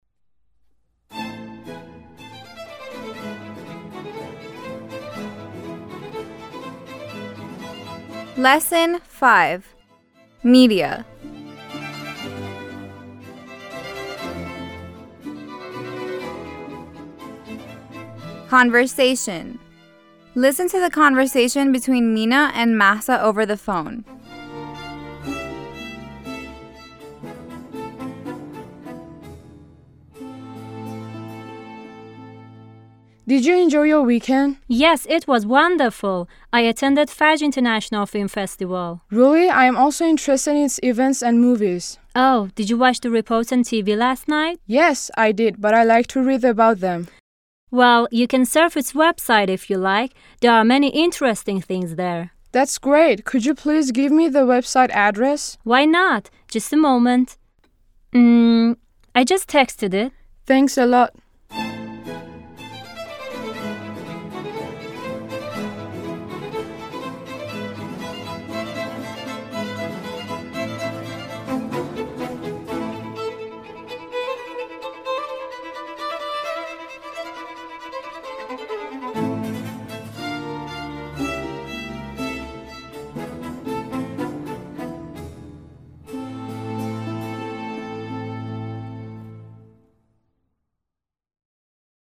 9-L5-Conversation